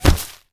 bullet_impact_grass_03.wav